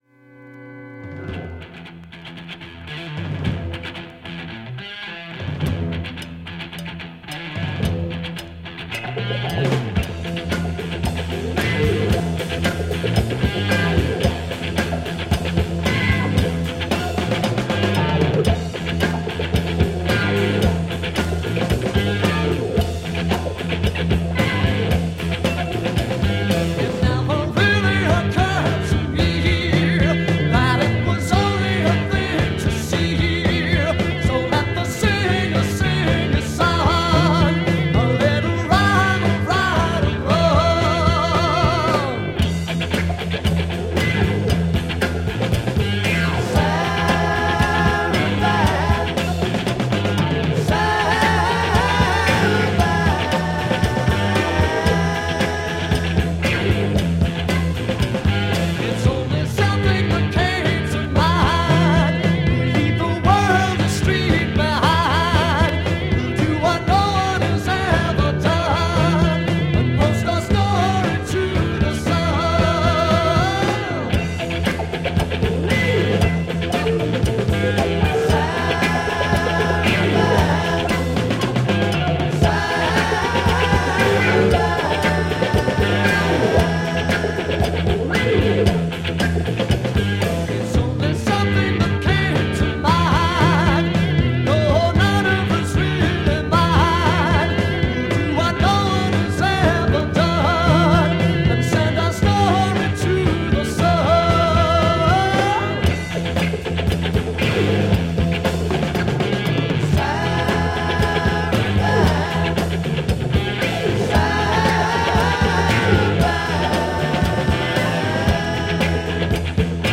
UK Heavy psych rock